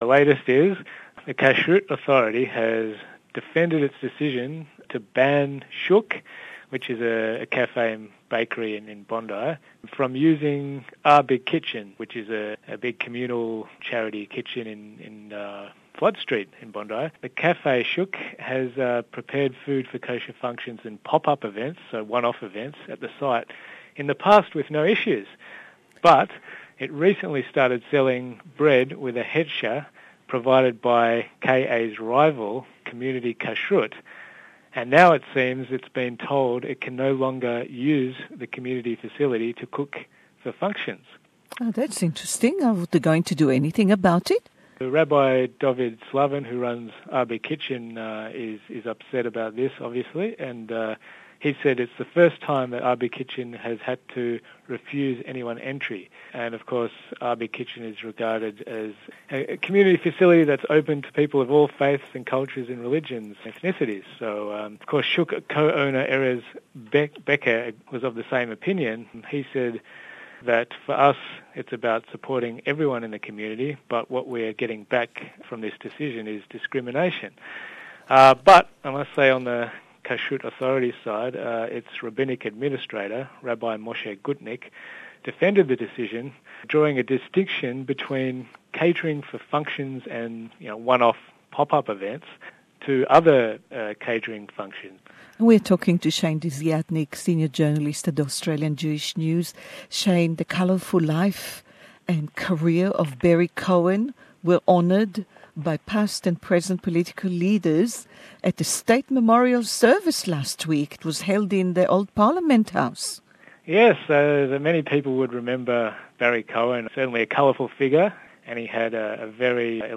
A unique chat that highlights the past week in the life of the Australian Jewish community